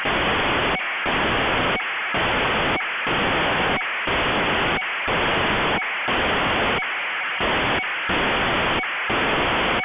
"цифра" на 74.525 МГц